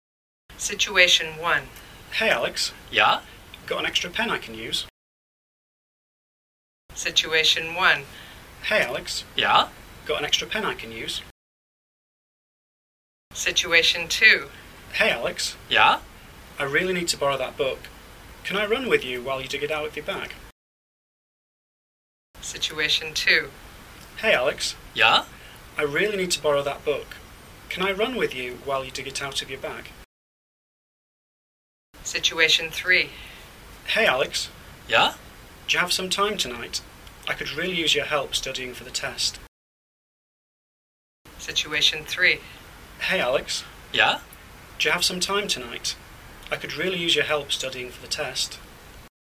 Ch4 " Follow-up Activity 4 - Natural - Repeat.mp3
Ch4-Follow-upActivity-Natural-Repeat.mp3